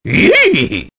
One of Donkey Kong's voice clips in Mario Kart DS